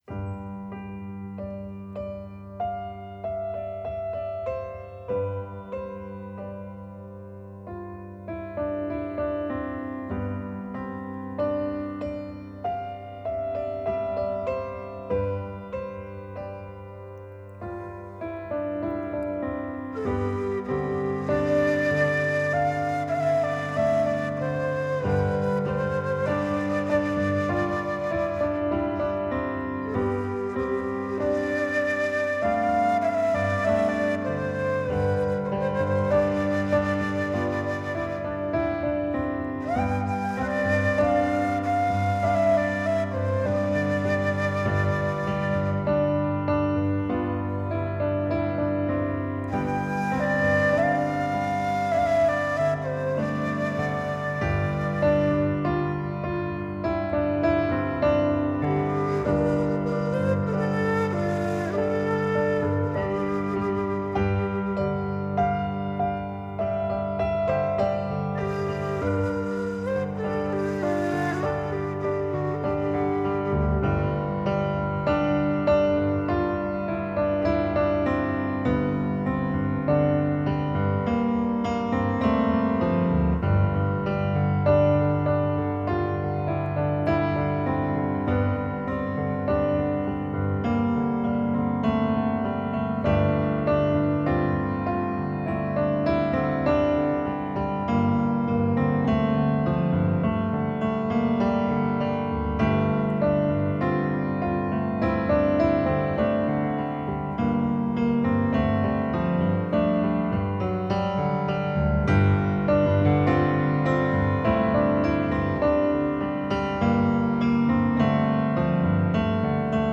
πιάνο